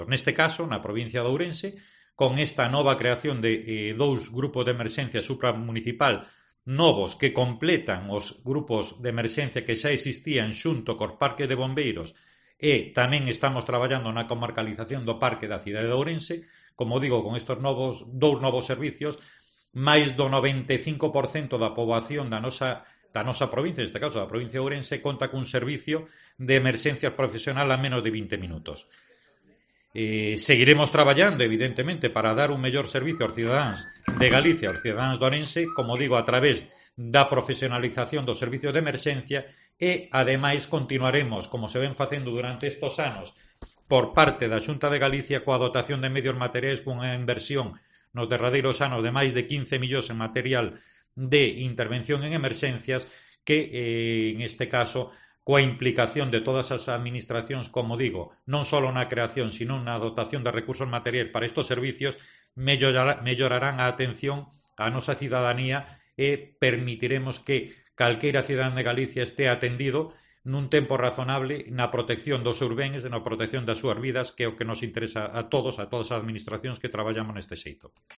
Declaraciones de Santiago Villanueva, director xeral de Emerxencias e Interior